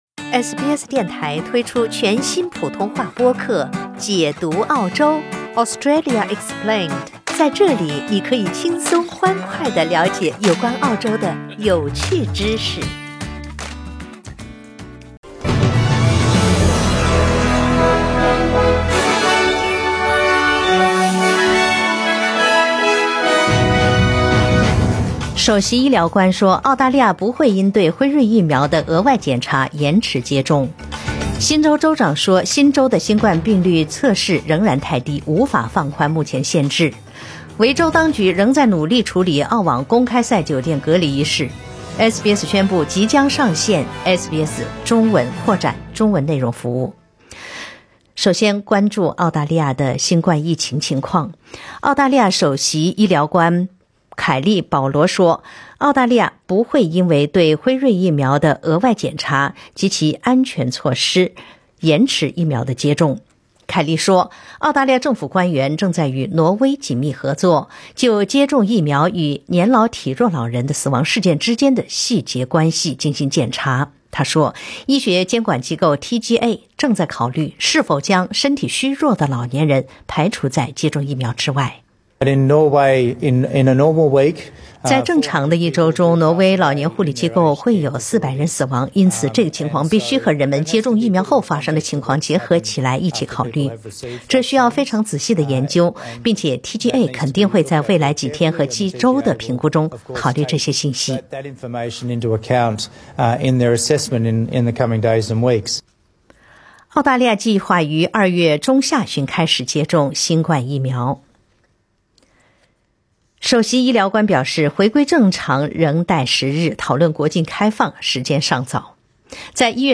SBS早新聞（1月20日）